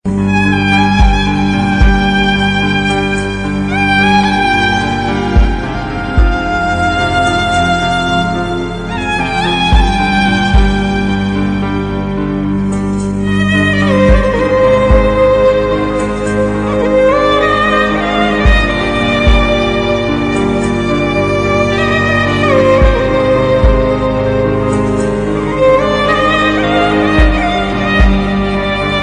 Arabisk Musik, Android, Musik, Ledsen musik